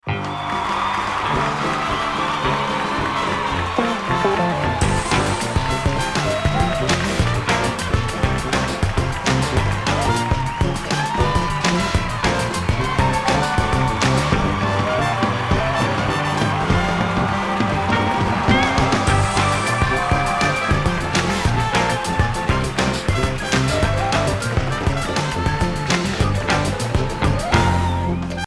Applause - Reality Bytes